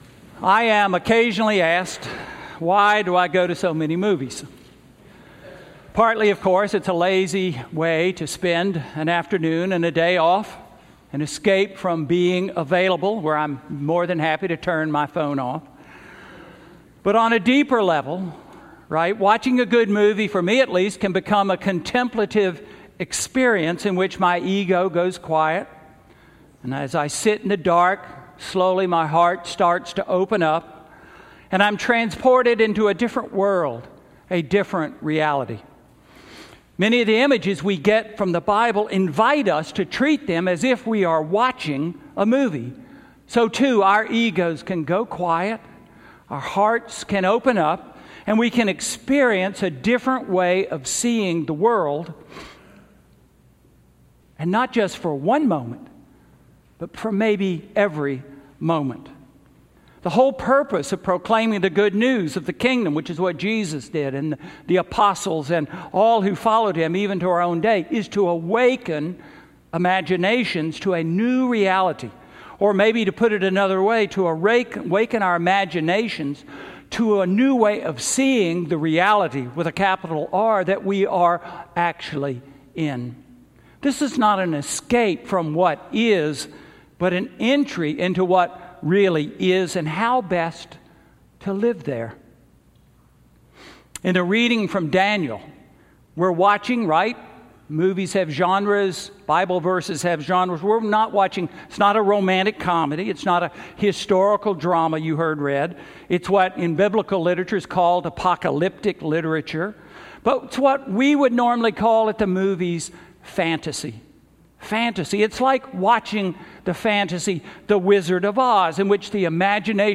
Sermon–Seeing Reality–November 25, 2018
Sermon: Last Sunday after Pentecost Year-B–Christ the King–November 25, 2018